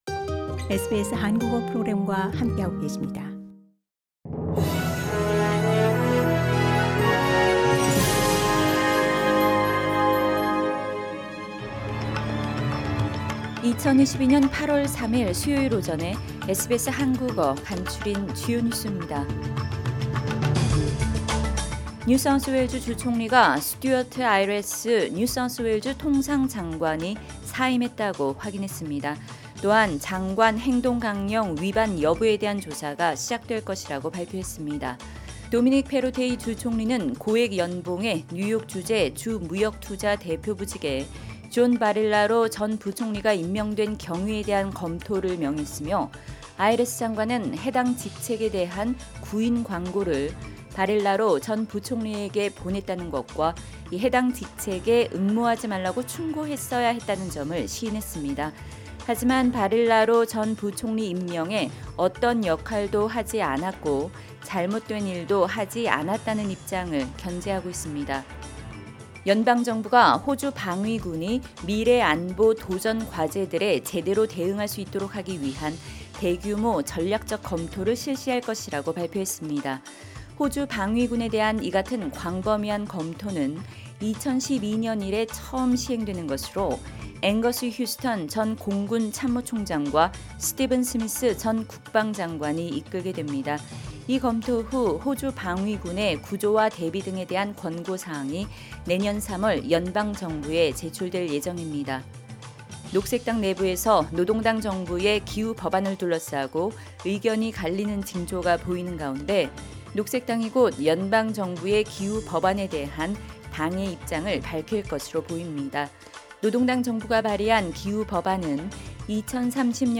SBS 한국어 아침 뉴스: 2022년 8월 3일 수요일
2022년 8월 3일 수요일 아침 SBS 한국어 간추린 주요 뉴스입니다.